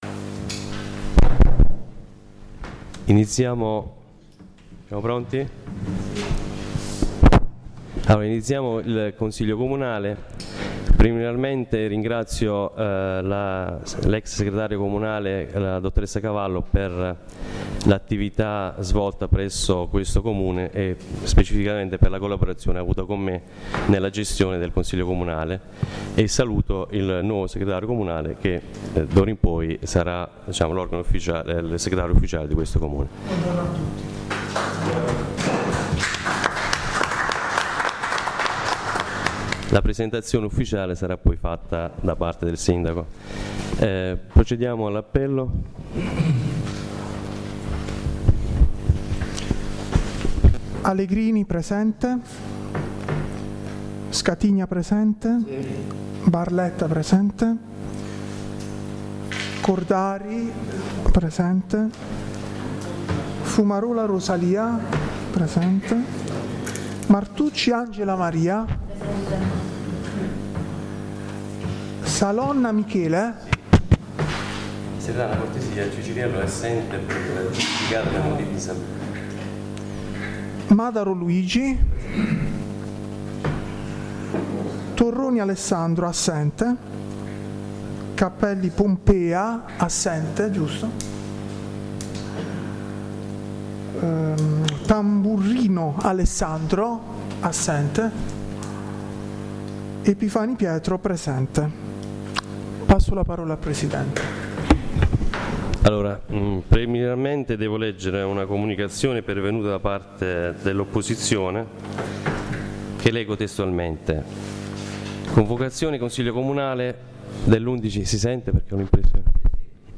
La registrazione audio del Consiglio Comunale di San Michele Salentino dell’11/10/2017.